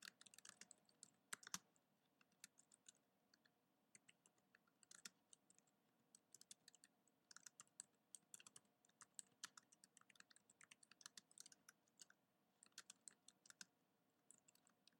Field recording 6
Typing-sounds.mp3